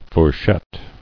[four·chette]